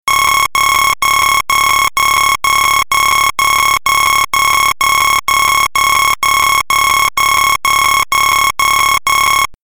دانلود آهنگ هشدار 20 از افکت صوتی اشیاء
دانلود صدای هشدار 20 از ساعد نیوز با لینک مستقیم و کیفیت بالا
جلوه های صوتی